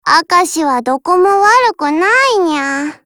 碧蓝航线:明石语音